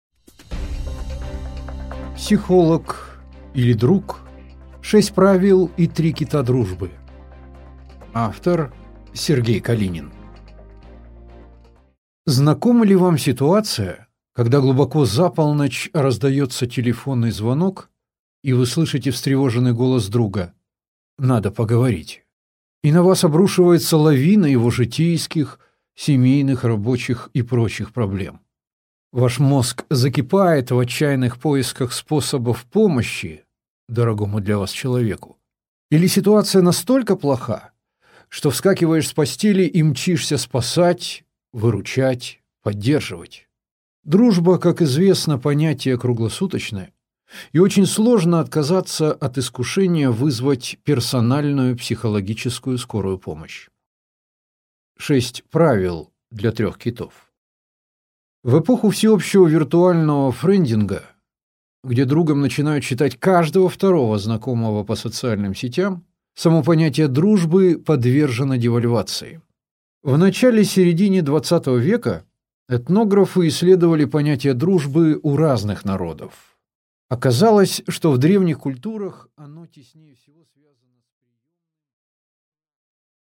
Аудиокнига Психолог или друг?